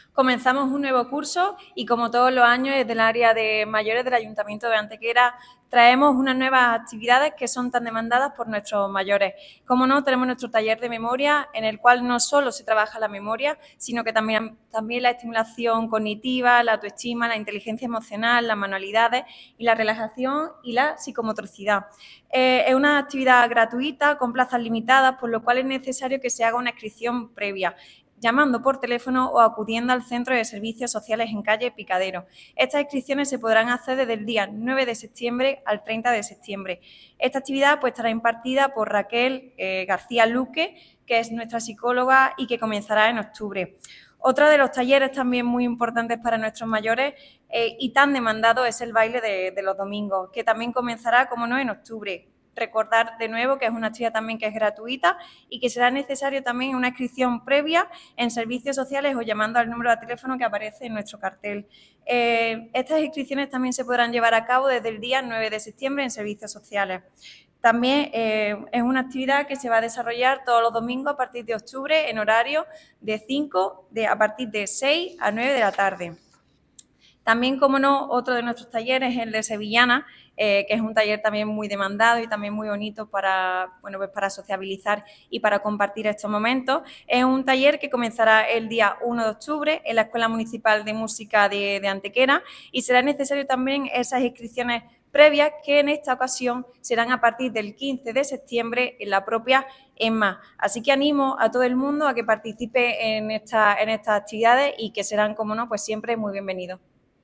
La concejal delegada María Sierras informa sobre esta iniciativa que ofrece tres propuestas con gran aceptación en ediciones anteriores:
Cortes de voz